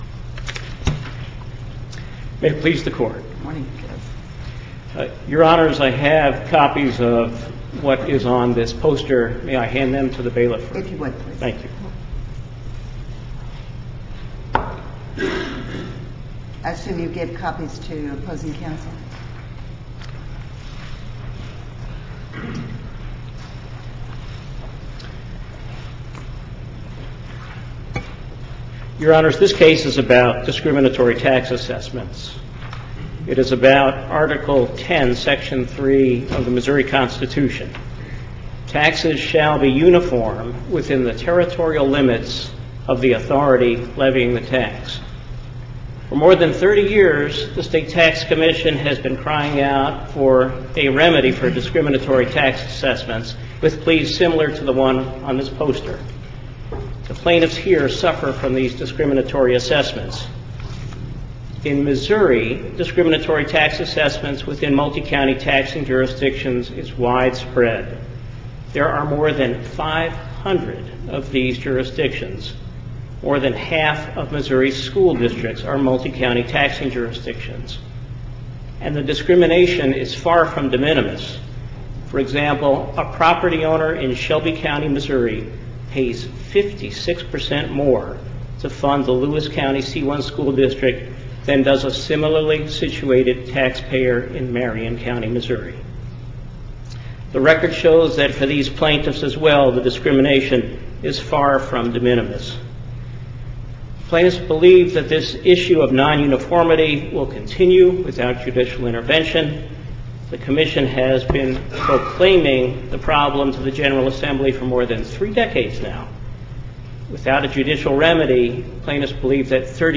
MP3 audio file of oral arguments in SC96016